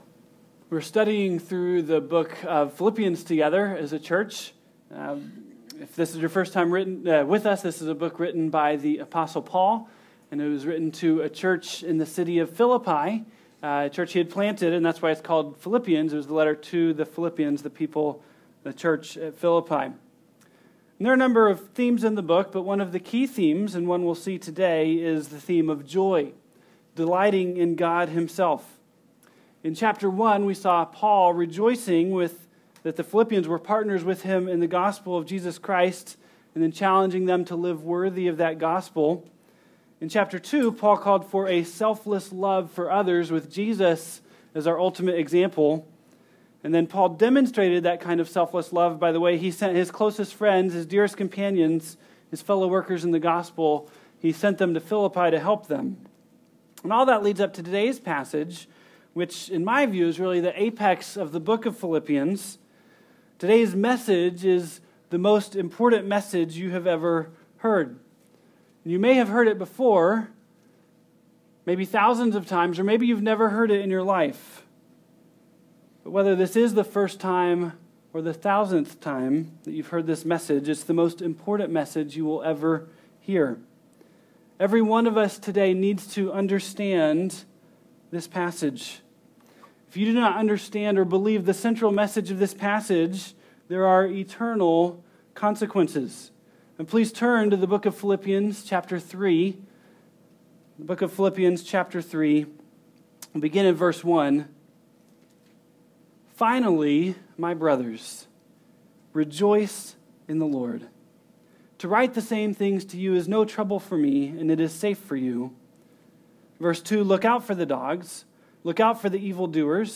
2014 ( Sunday AM ) Bible Text